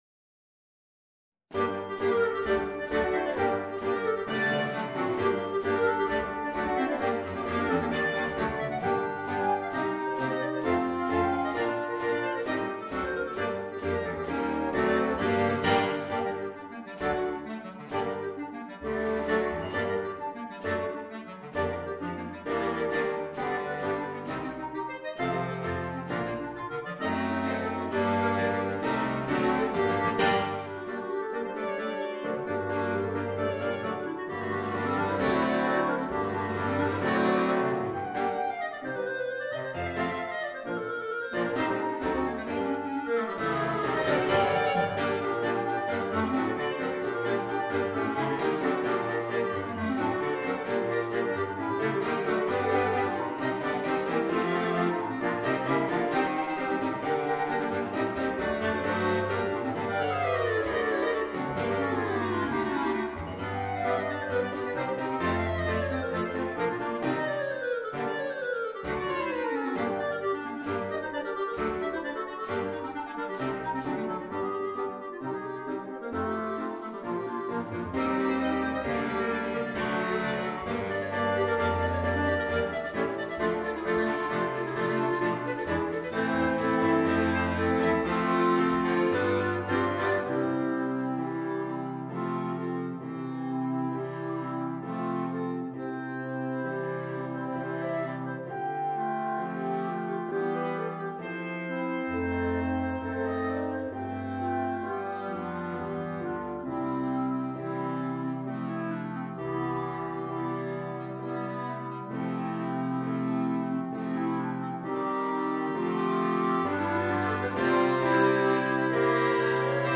8 Klarinetten (Klavier, Pauken, Schlagzeug optional)